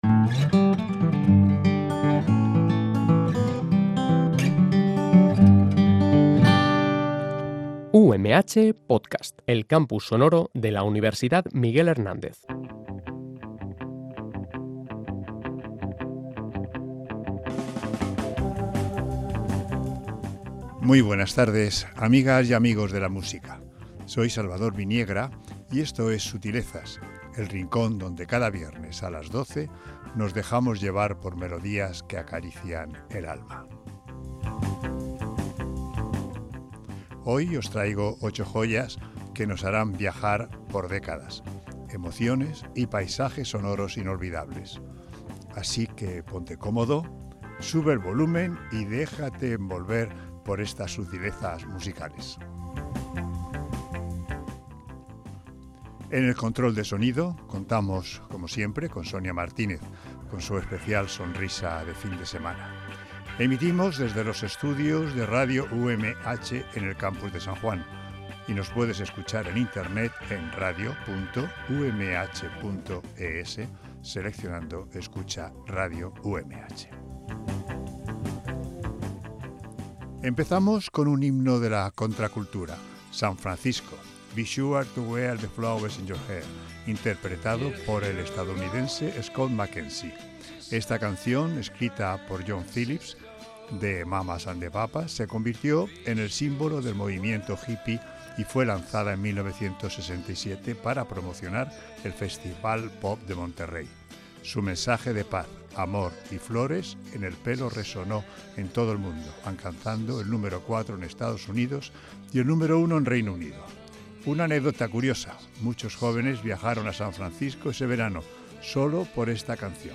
Seguimos con una balada que elevó corazones
balada soul-pop con arreglos sofisticados